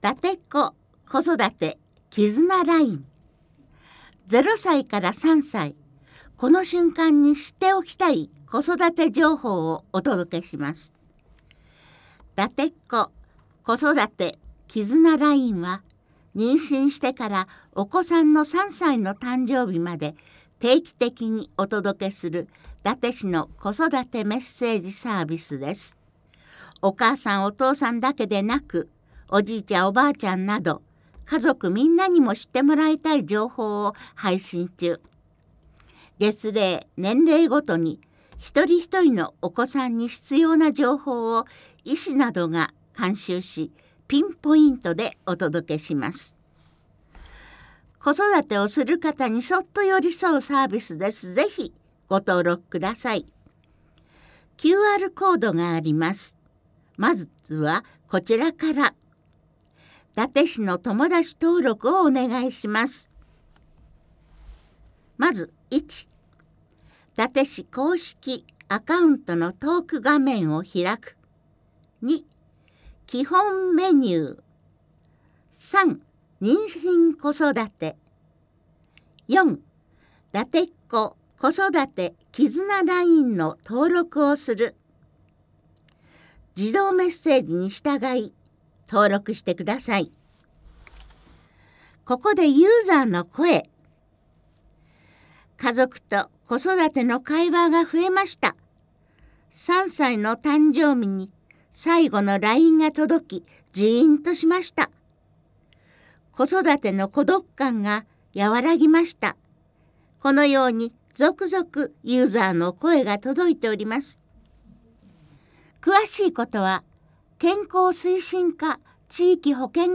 ■朗読ボランティア「やまびこ」が音訳しています